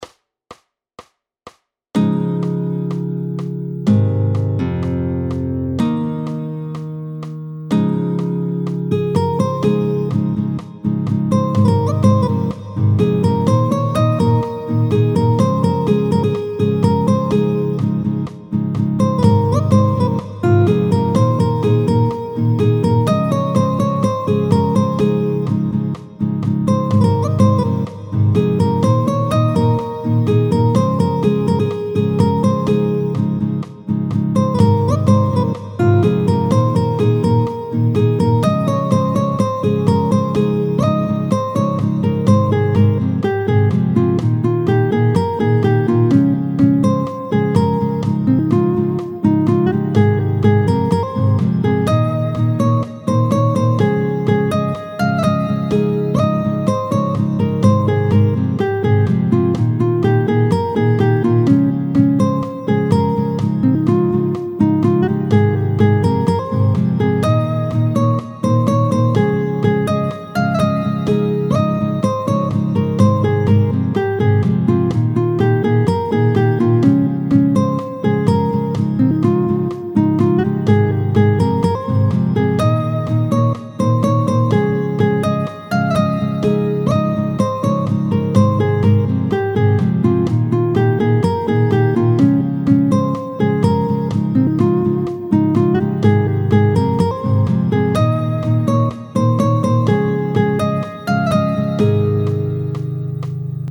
tempo 125